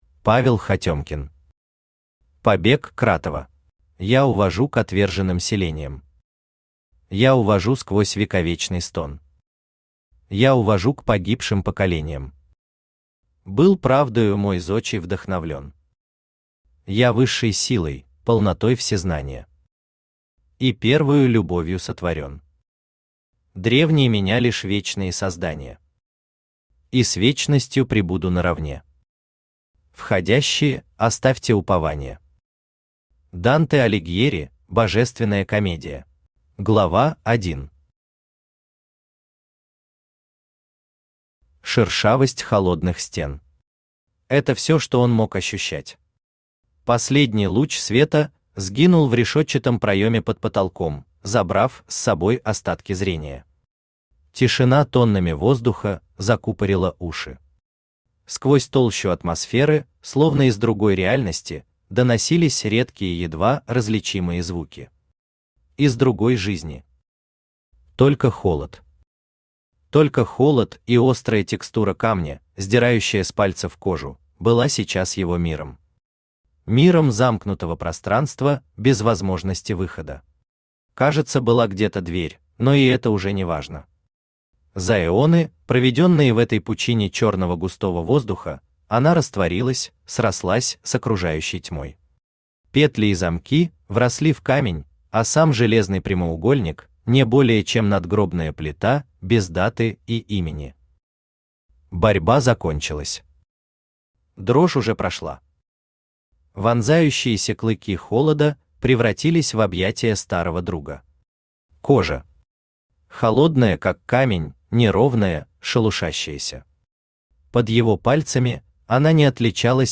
Аудиокнига Побег Кратова | Библиотека аудиокниг
Aудиокнига Побег Кратова Автор Павел Андреевич Хатемкин Читает аудиокнигу Авточтец ЛитРес.